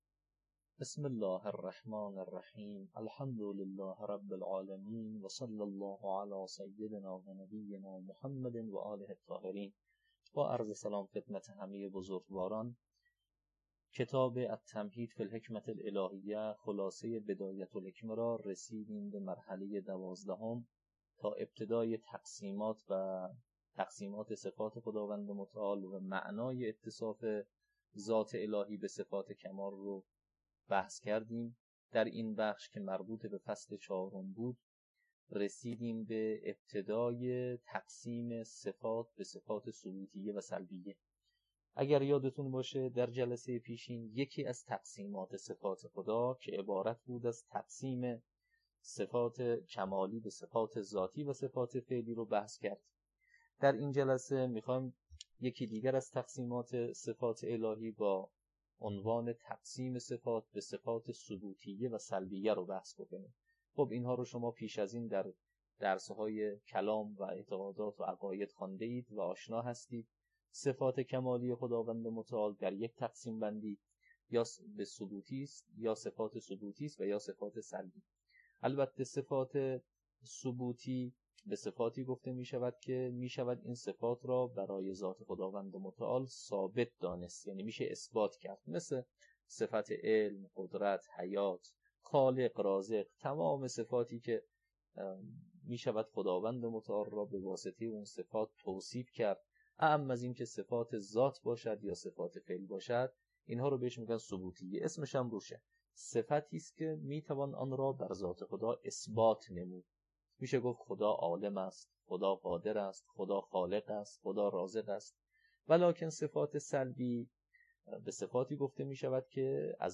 التمهيد في الحكمة الهية (خلاصه بدایه الحکمه) - تدریس